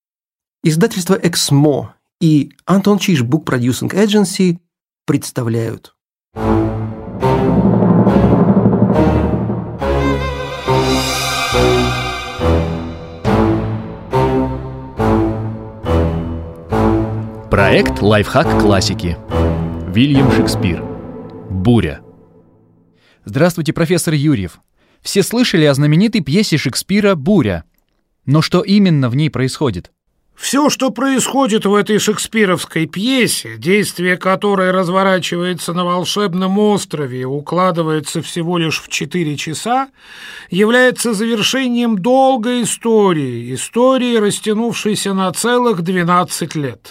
Аудиокнига Лайфхак классики. Буря | Библиотека аудиокниг